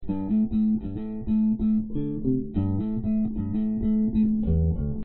描述：Fender电贝司上的Bassline，带有滤波效果。
Tag: 低音 电动 挡泥板 线